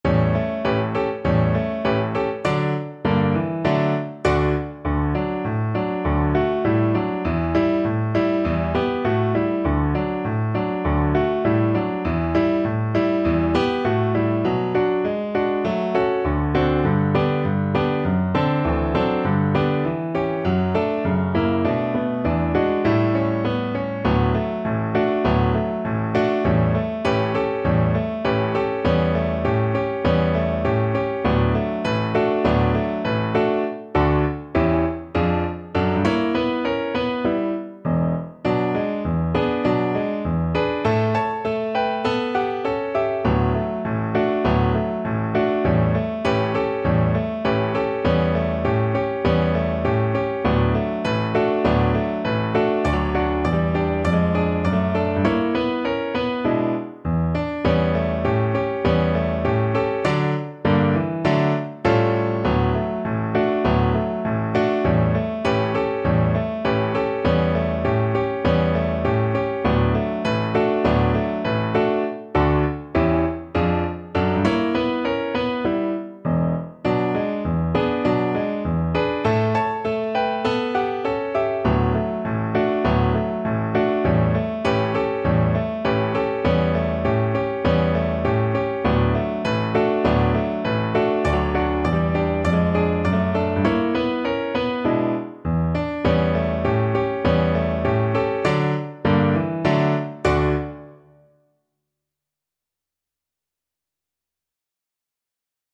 Moderato =c.100
2/2 (View more 2/2 Music)
Pop (View more Pop Viola Music)